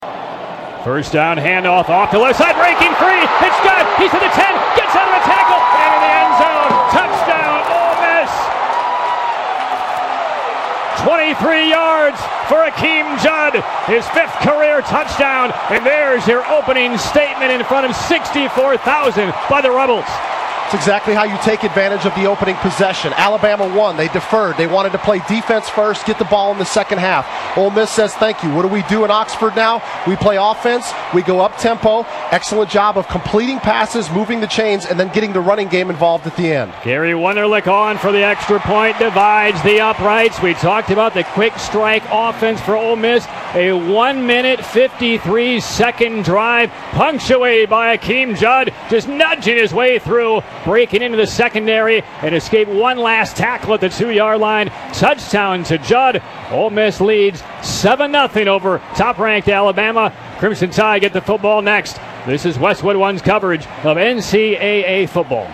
national radio call of Alabama Ole Miss today - Courtesy Westwood One